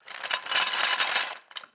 effect__trap_chain.wav